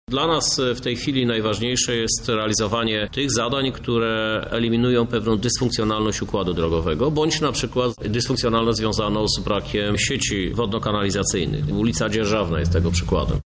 „Dla nas najważniejsze są 2 typy inwestycji” – mówi Krzysztof Żuk, prezydent Lublina.